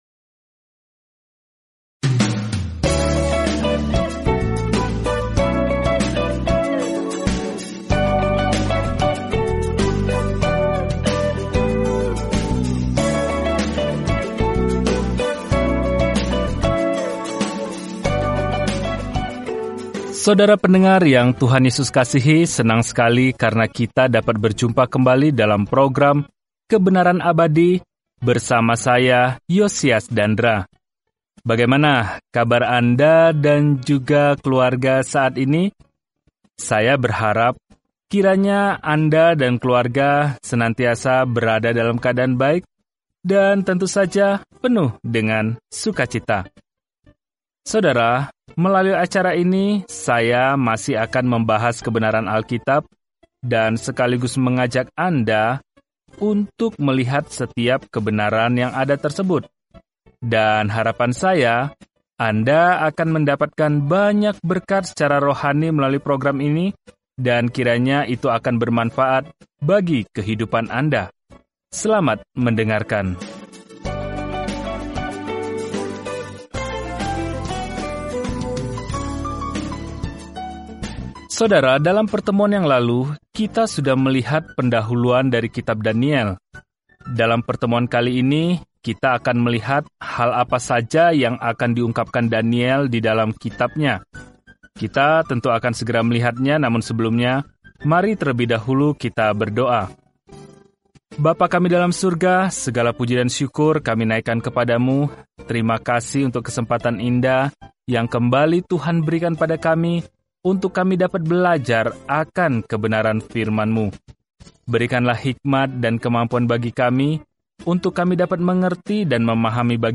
Firman Tuhan, Alkitab Daniel 1:2-13 Hari 1 Mulai Rencana ini Hari 3 Tentang Rencana ini Kitab Daniel merupakan biografi seorang pria yang percaya kepada Tuhan dan visi kenabian tentang siapa yang pada akhirnya akan memerintah dunia. Telusuri Daniel setiap hari sambil mendengarkan studi audio dan membaca ayat-ayat tertentu dari firman Tuhan.